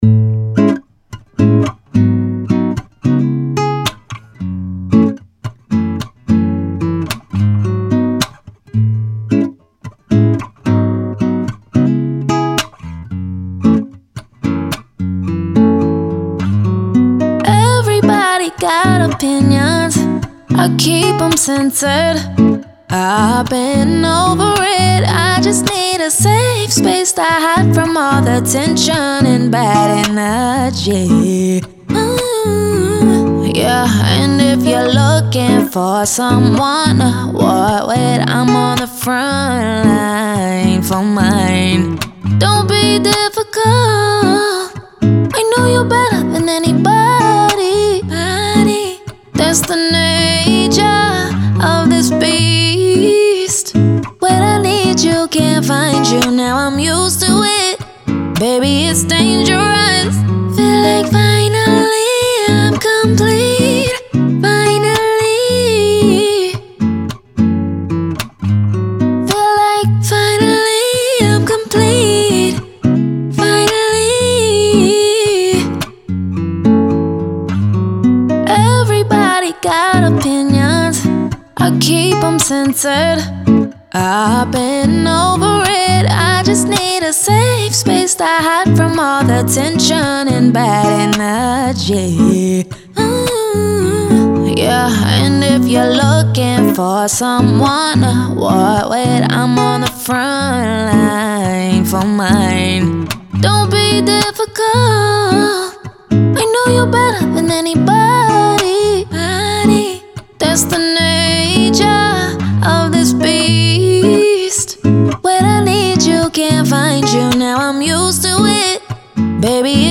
Acoustic, R&B
C# Minor